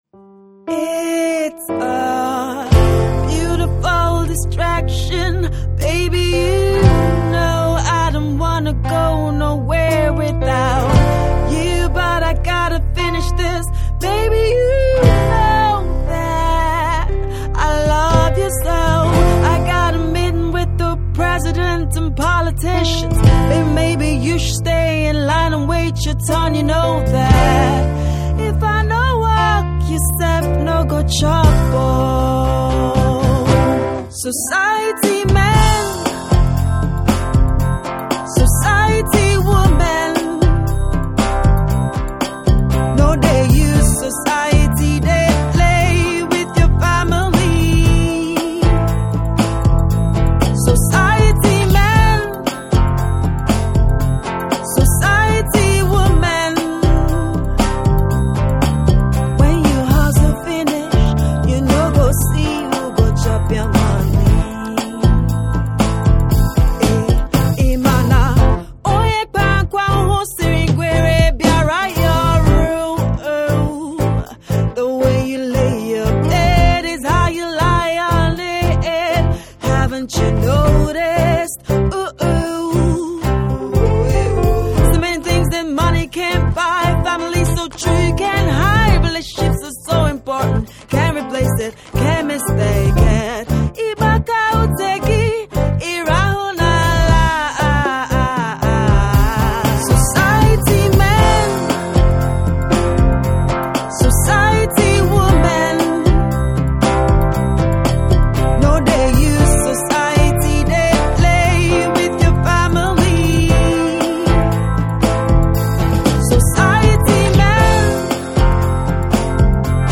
Soul
Afrosoul/soft rock
pure soul as the heartbeat of her music